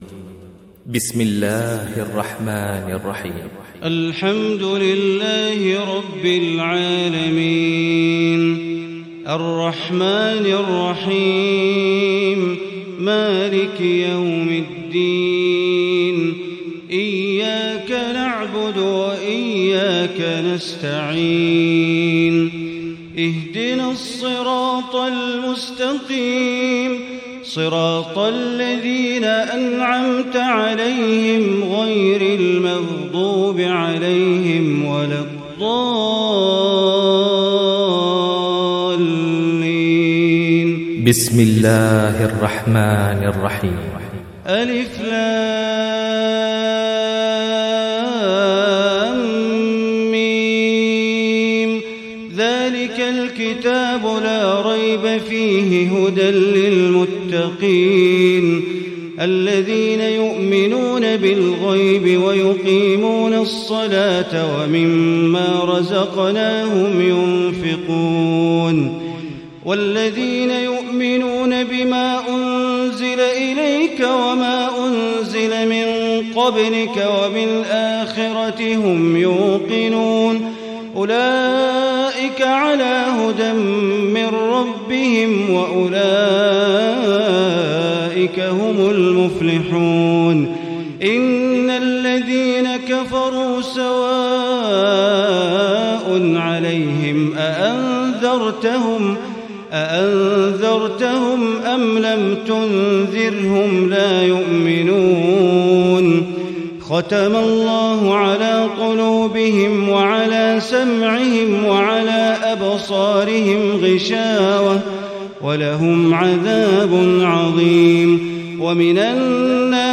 تهجد ليلة 21 رمضان 1437هـ من سورة البقرة (1-91) Tahajjud 21 st night Ramadan 1437H from Surah Al-Baqara > تراويح الحرم المكي عام 1437 🕋 > التراويح - تلاوات الحرمين